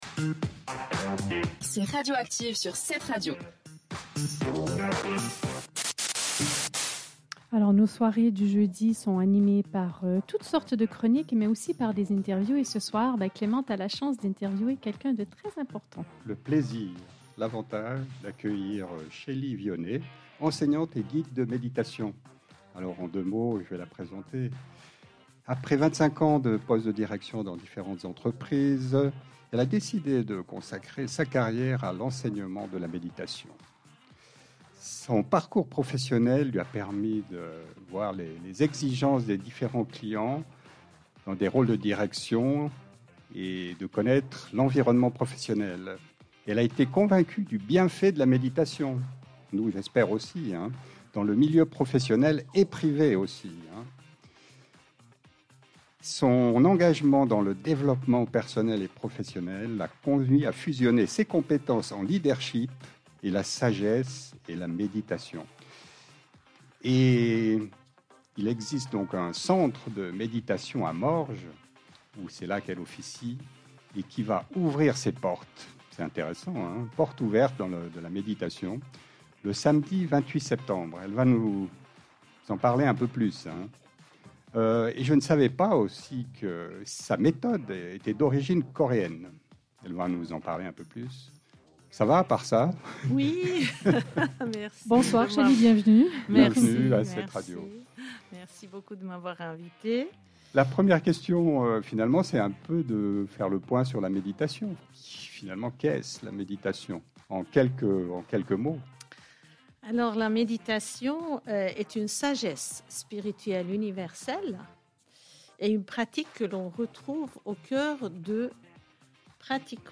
La méditation, vous connaissez? Interview
Un petit exercice de méditation sur nos ondes pour mieux se concentrer et faire le plein d’énergie.